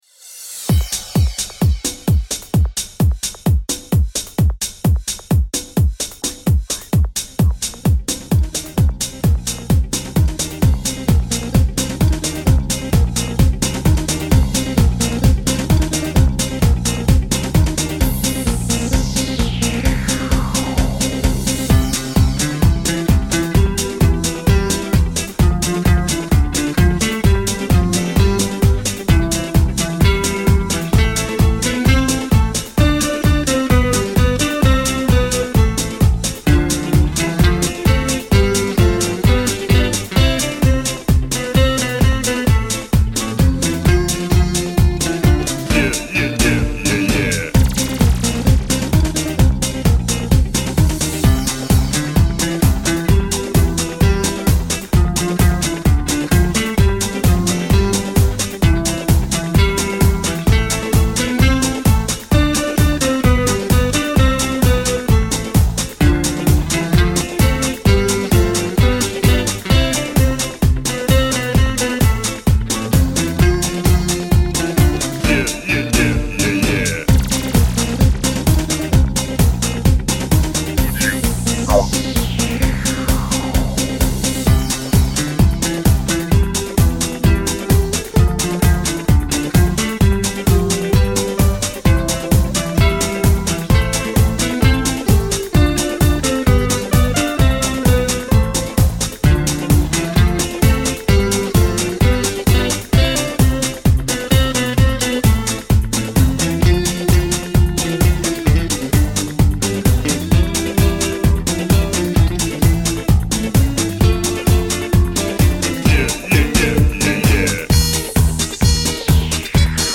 Музыкальные минусовки